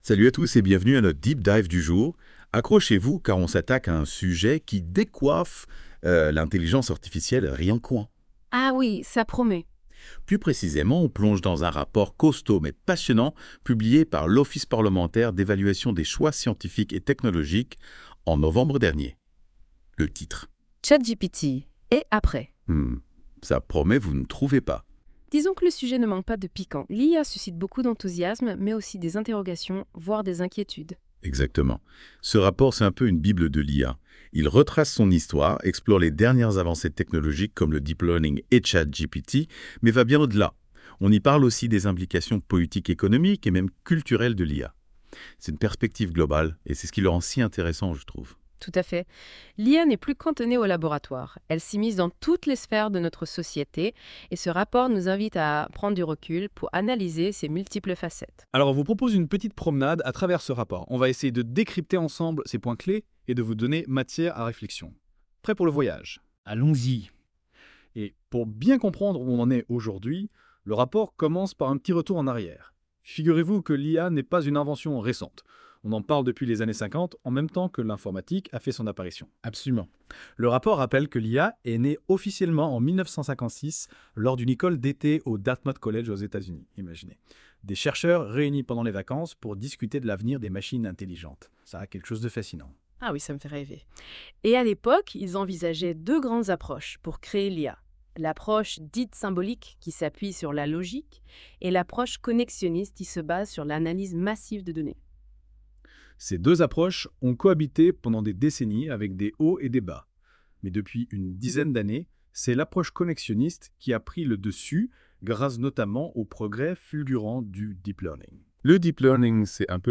Débat en podcast à écouter ici